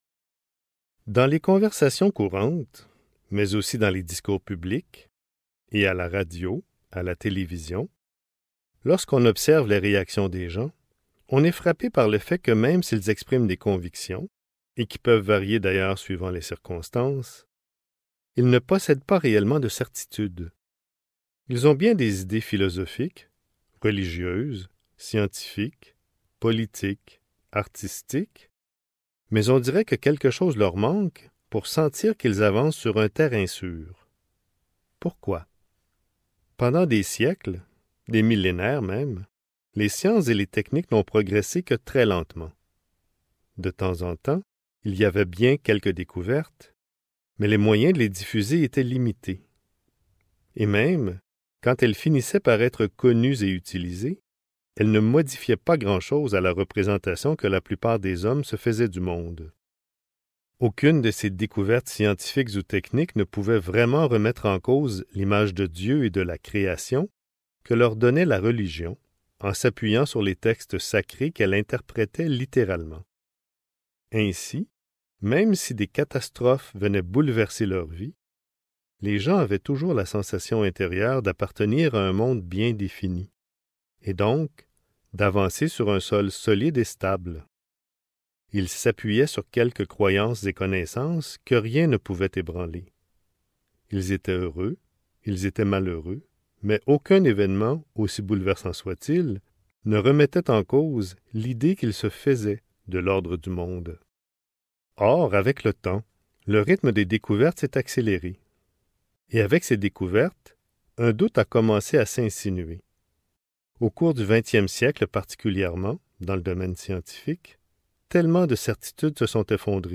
L'amour plus grand que la foi (Livre audio | CD MP3) | Omraam Mikhaël Aïvanhov